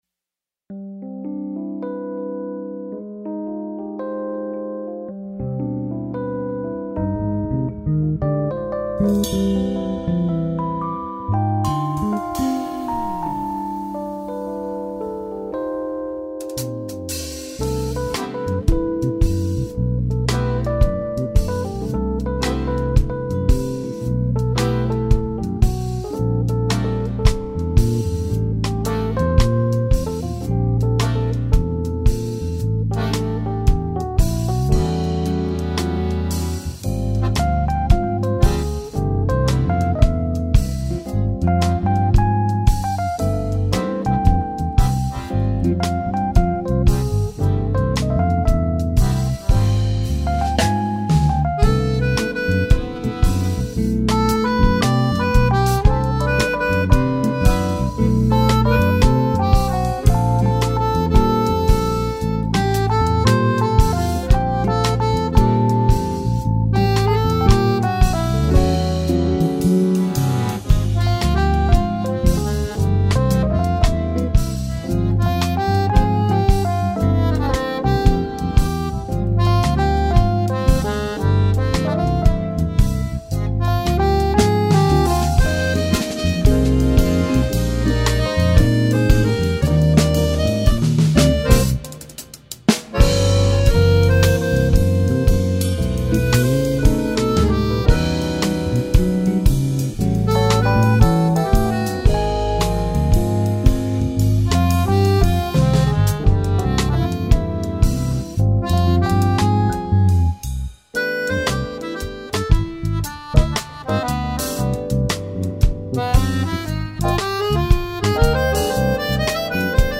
2804   04:35:00   Faixa: 2    Jazz
Bateria, Percussão
Baixo Elétrico 6, Violao Acústico 6
Guitarra, Viola
Acoordeon